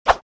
swing_2.wav